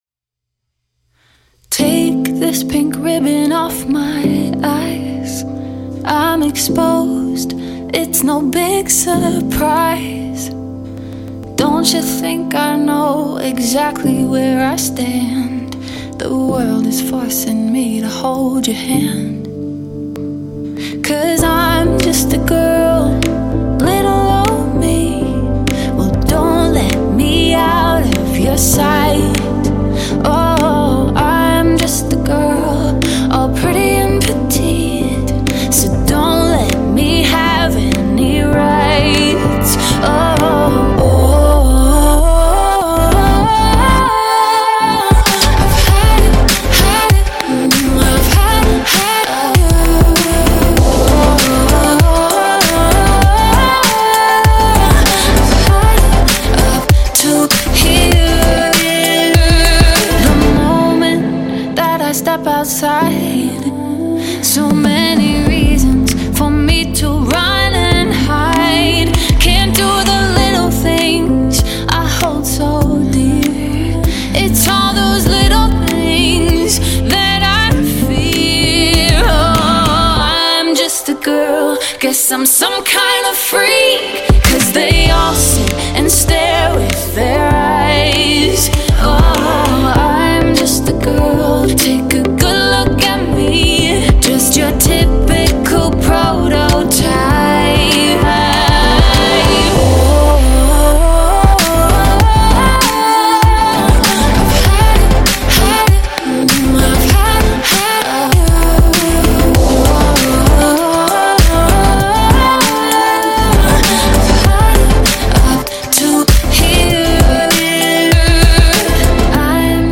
# Pop